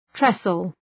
Προφορά
{‘tresəl}